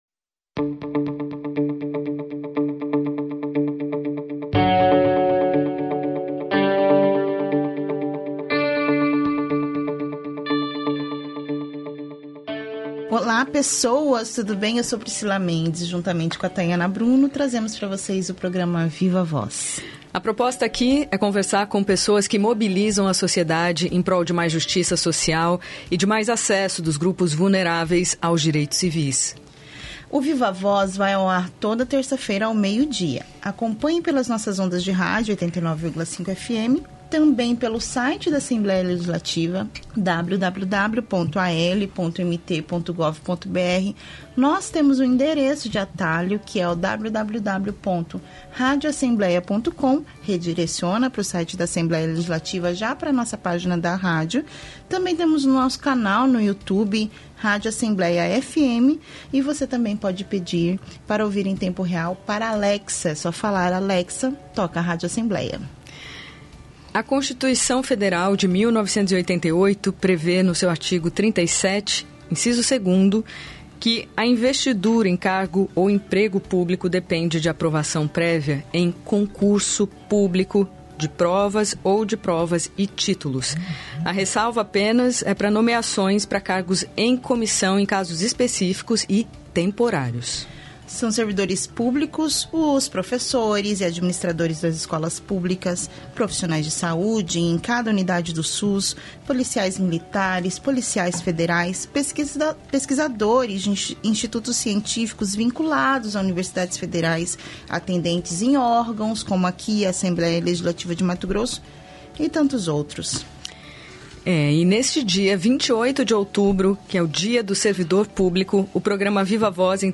o Viva-voz entrevista